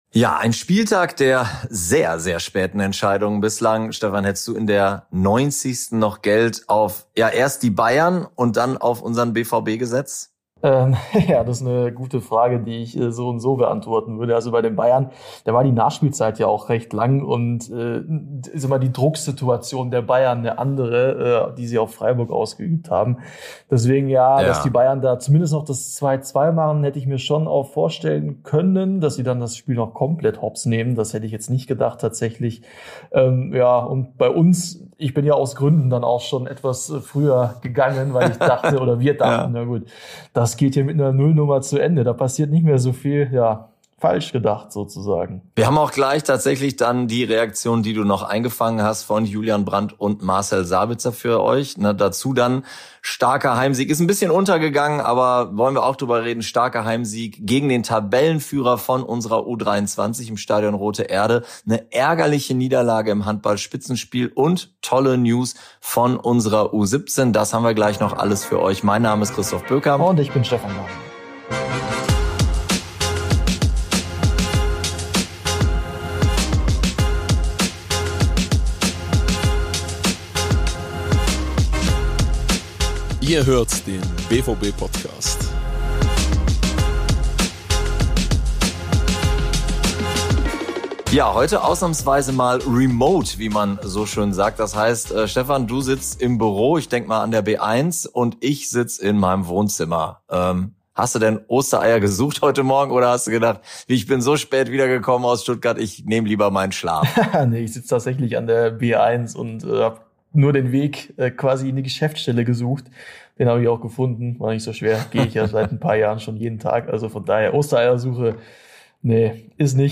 Zudem hört ihr Julian Brandt und Marcel Sabitzer. Ebenso bespricht das Duo den Heimsieg der U23 gegen den Spitzenreiter Fortuna Köln trotz langer Unterzahl, den Einzug der U17 in die Endrunde um die Deutsche Meisterschaft und hintenraus geht's noch um die bittere Niederlage der Handballerinnen in der Bundesliga.